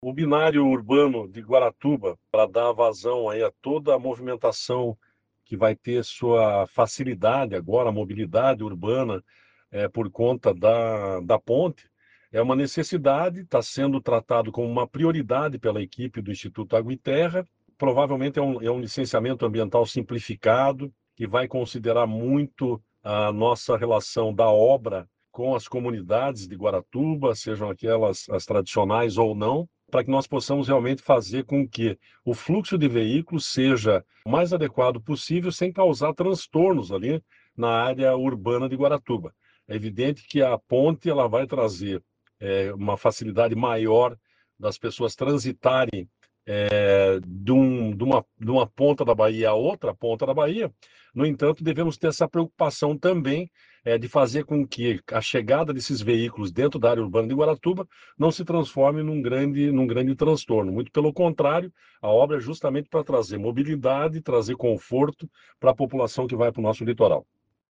Sonora do secretário do Desenvolvimento Sustentável, Everton Souza, sobre o novo binário de Guaratuba